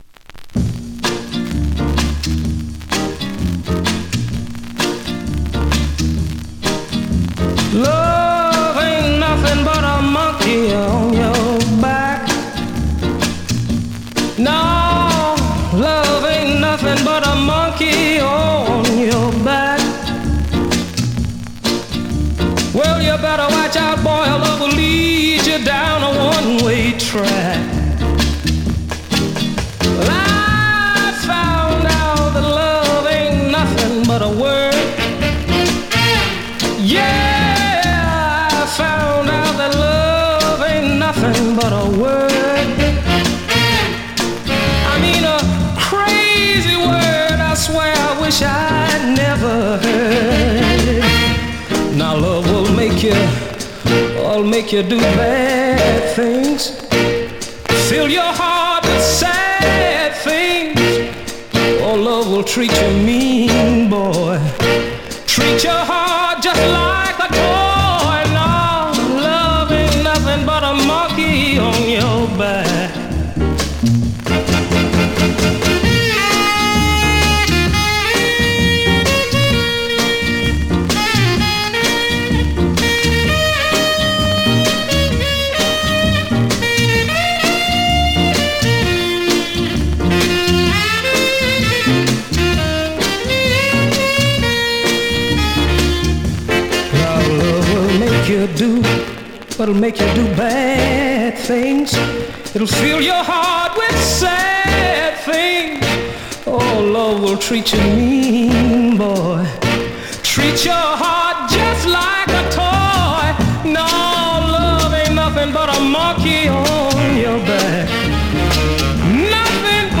Vinyl has a few light marks plays great .
Great classic mid-tempo Rnb / Mod dancer .
R&B, MOD, POPCORN